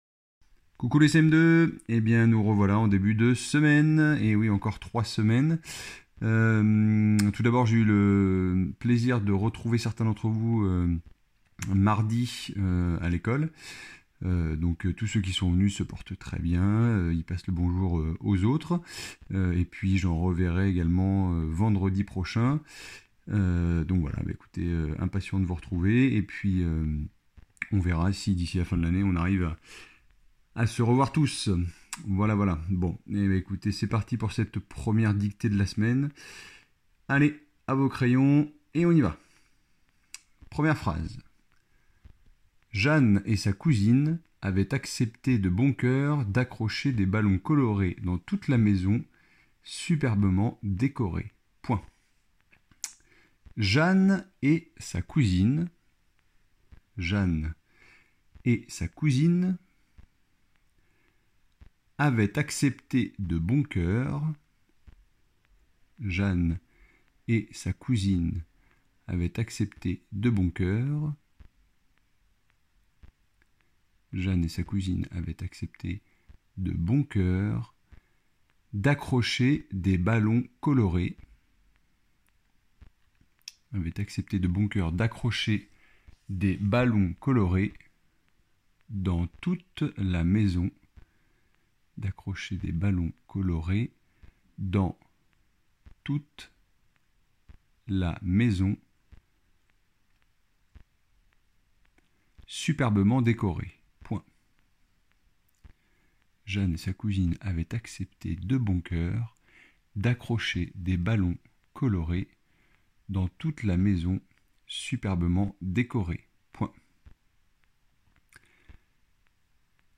- Dictée :
dictee-du-lundi.mp3